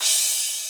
cym1_4.ogg